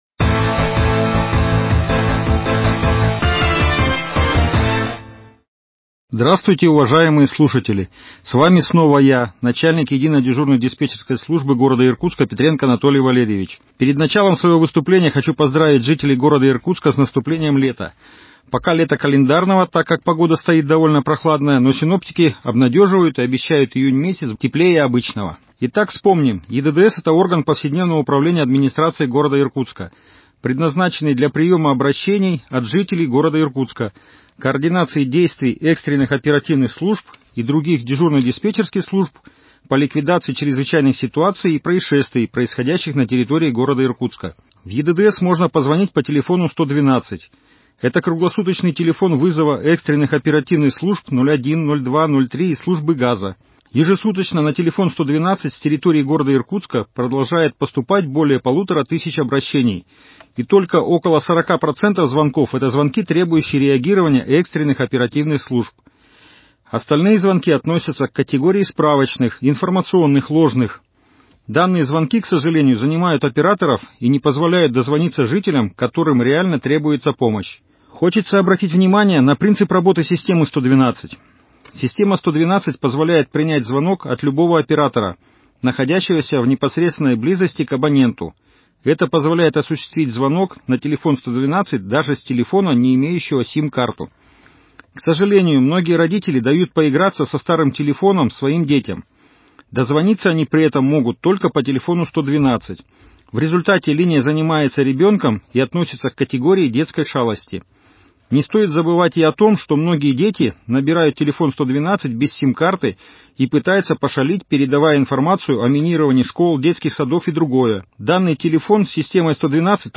Радиожурнал МКУ «Безопасный город» информирует: Работа единой диспетчерской дежурной службы 01.06.2021